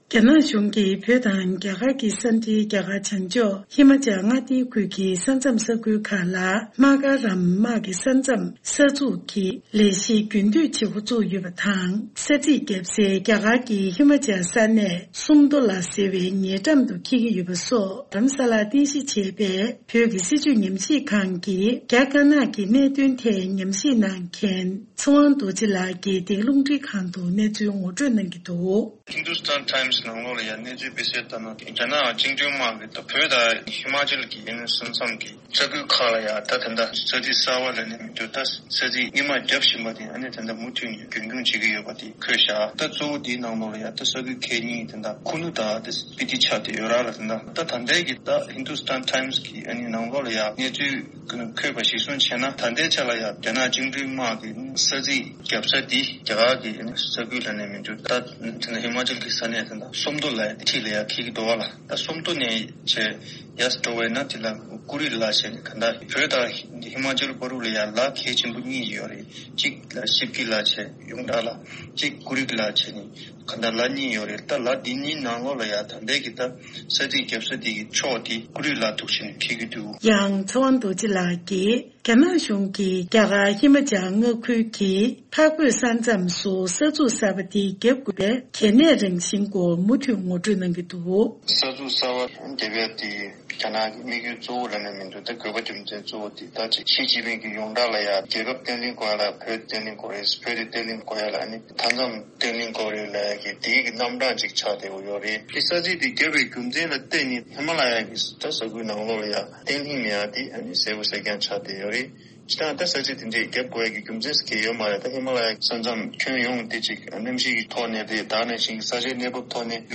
༄༅།།གསར་འགྱུར་དང་འབྲེལ་བའི་ལེ་ཚན་ནང།